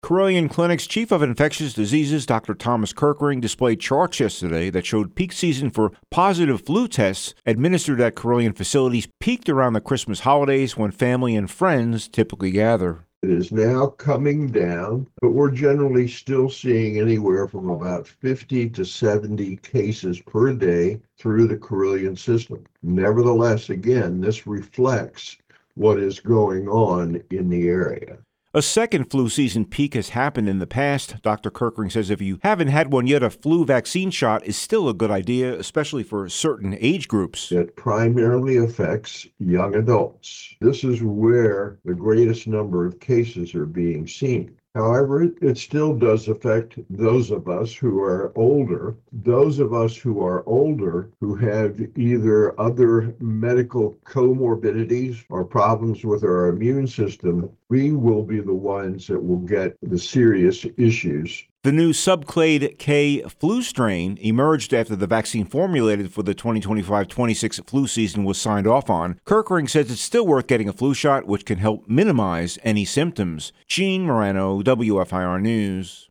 The post Flu cases peaked locally around Christmas first appeared on News/Talk 960-AM & FM-107.3 WFIR.